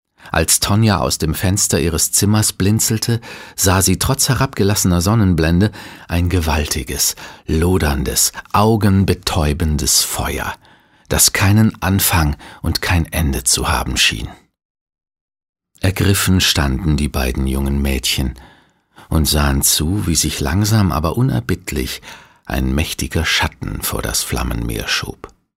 Profi-Sprecher deutsch.
Sprechprobe: Industrie (Muttersprache):
german voice over artist